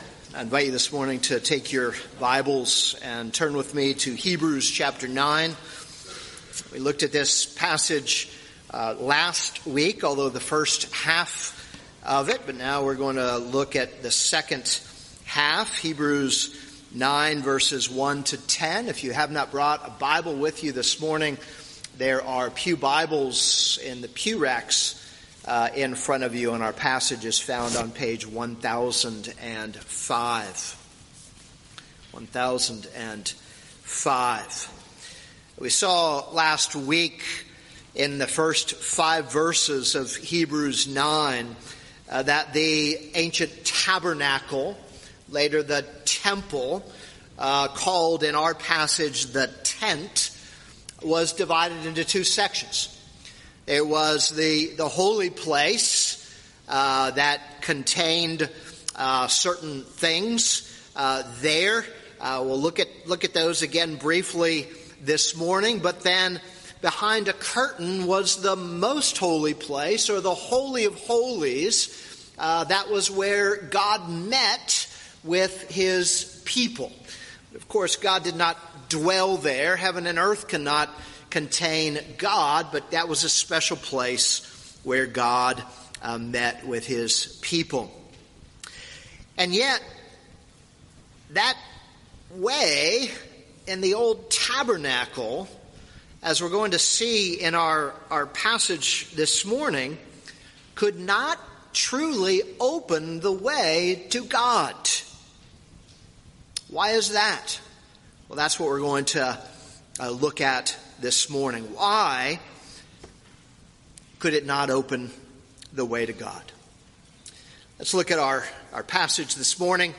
This is a sermon on Hebrews 9:1-10.